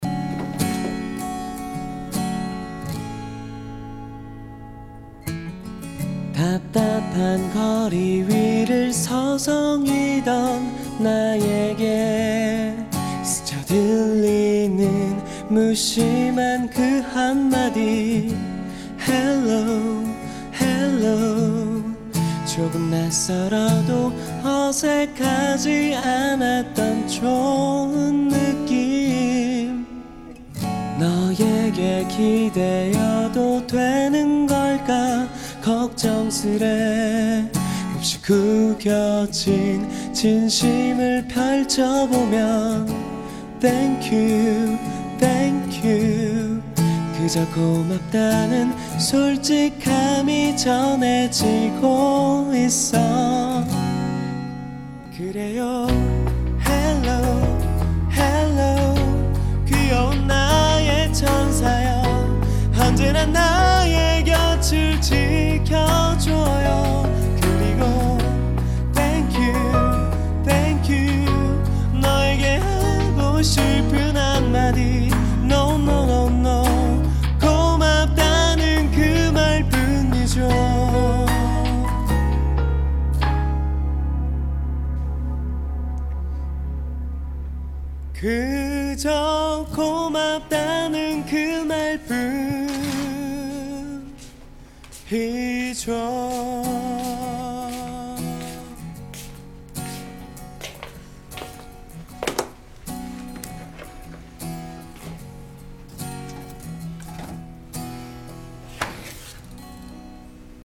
Ballad version